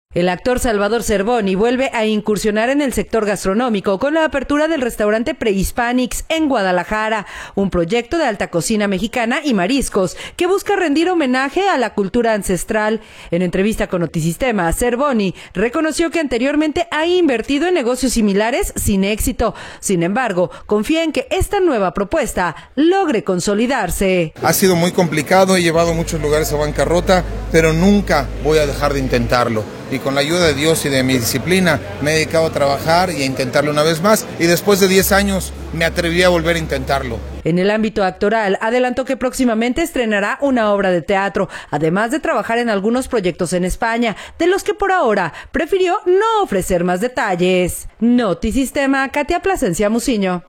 En entrevista con Notisistema, Zerboni reconoció que […]